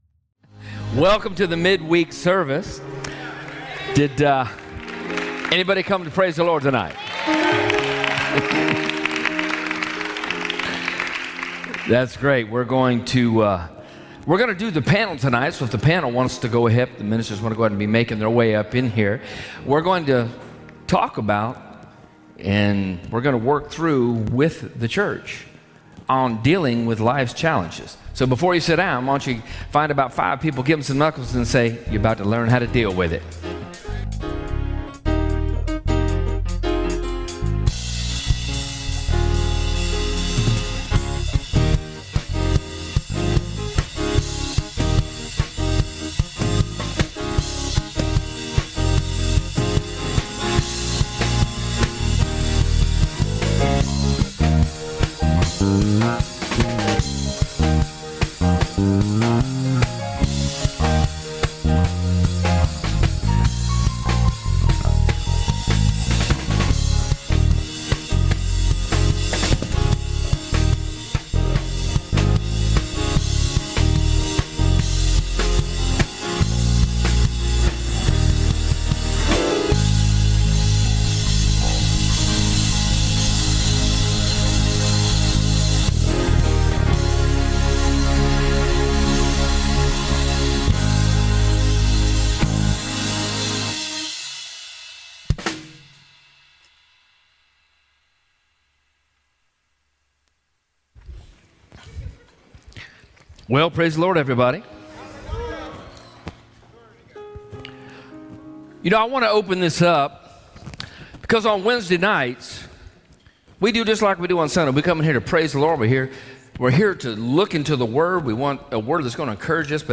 1 Heart to Heart - Panel Discussion